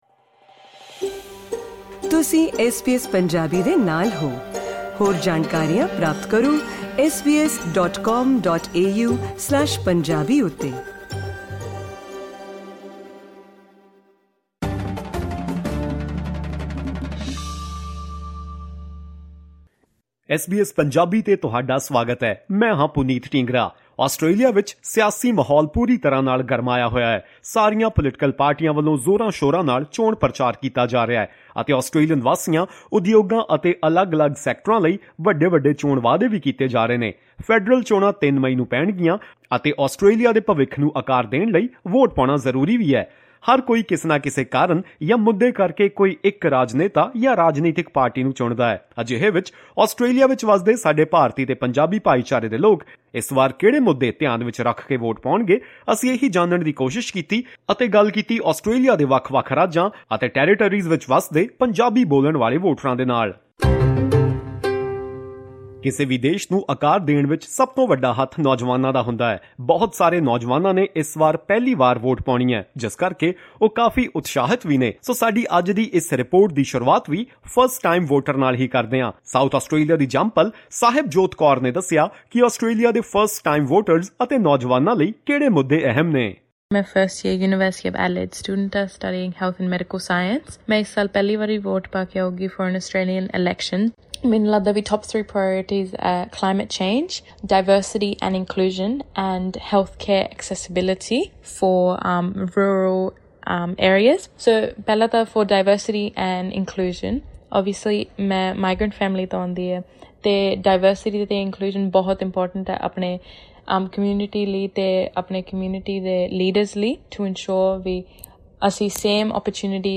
What are Punjabi-speaking Australian voters thinking about as they head to the polls? To find out, we spoke with Australian Punjabi voters from every state and territory — Victoria, Tasmania, New South Wales, South Australia, Western Australia, Queensland, Northern Territory, and the ACT.